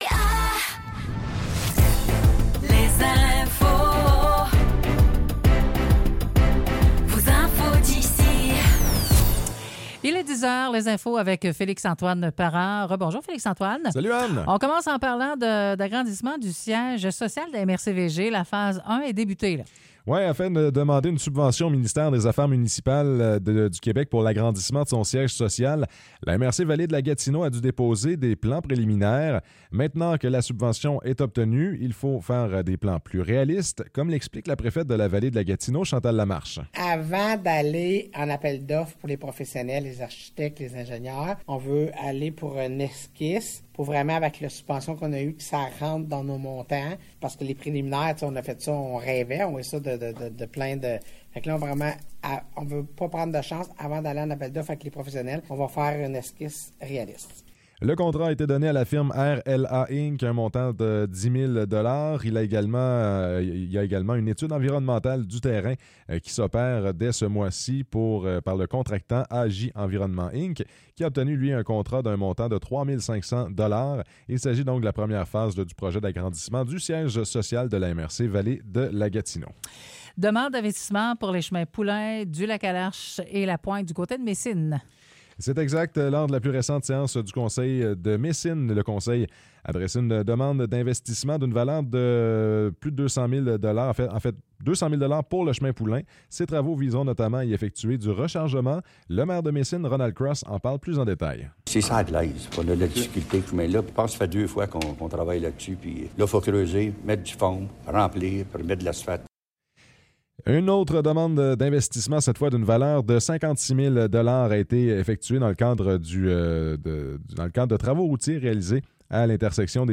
Nouvelles locales - 15 janvier 2024 - 10 h